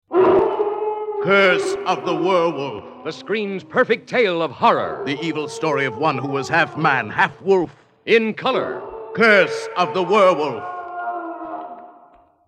Howl With Some Werewolf Movie Radio Spots